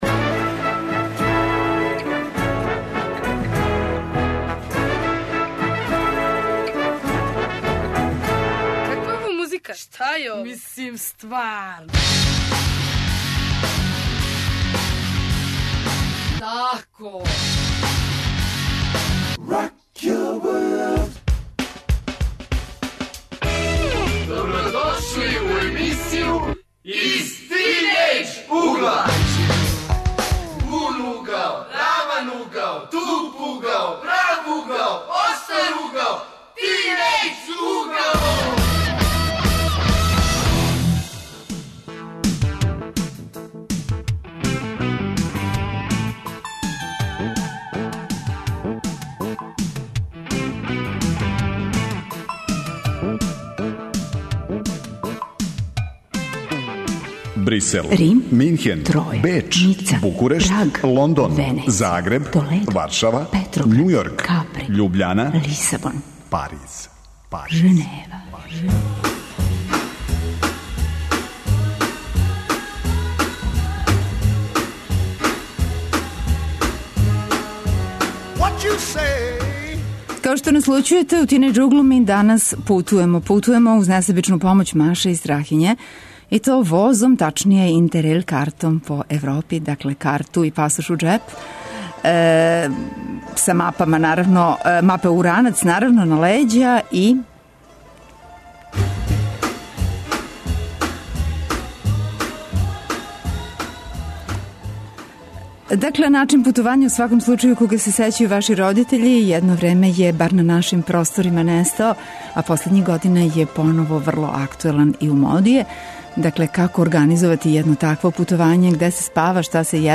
Гости су студенти који су се управо вратили са путовања по Европи, уз помоћ inter rail карте.